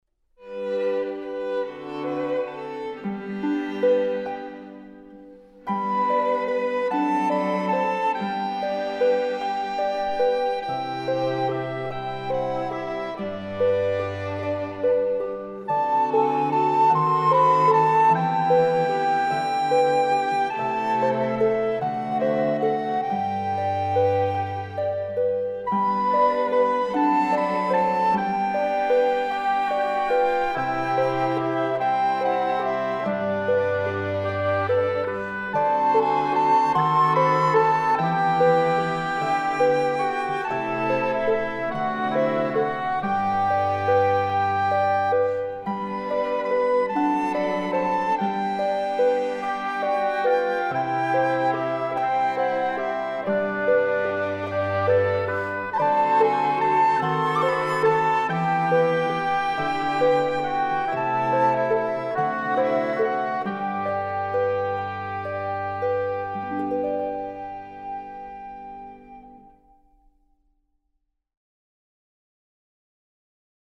Gattung: für Sopranblockflöte und Klavier